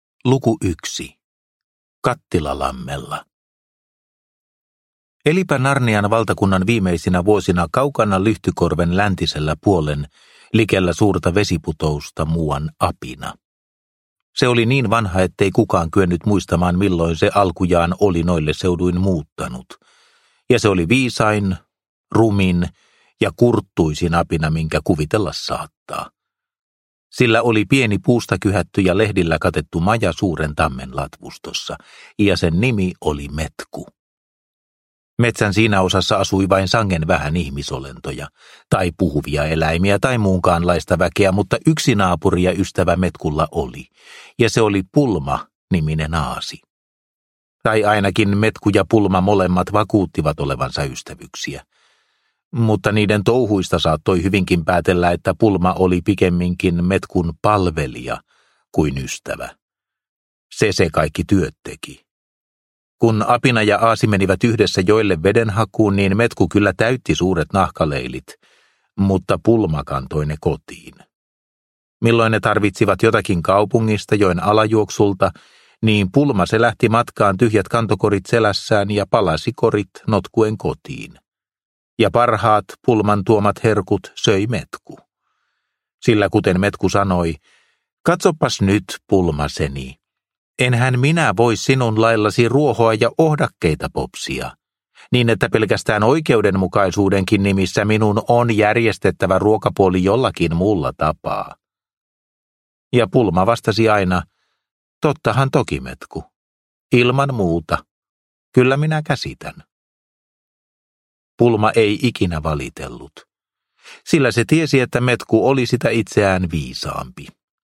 Narnian viimeinen taistelu – Ljudbok – Laddas ner